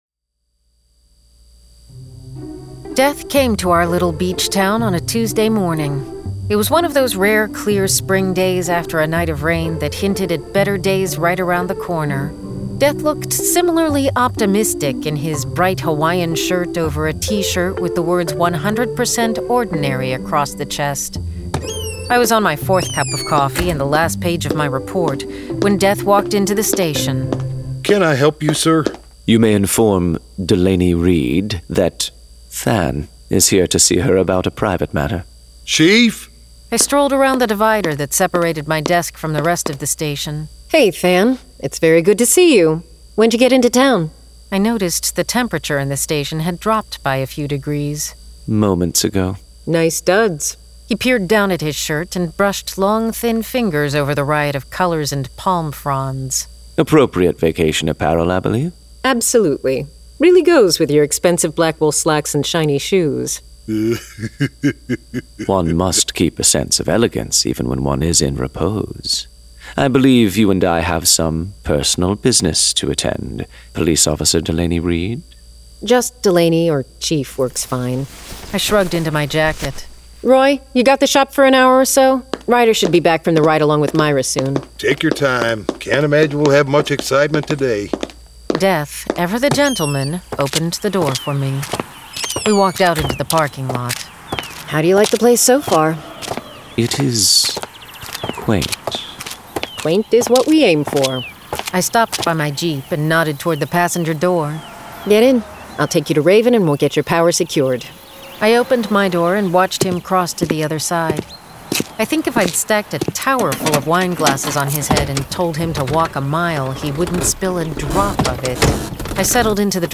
Full Cast. Cinematic Music. Sound Effects.
[Dramatized Adaptation]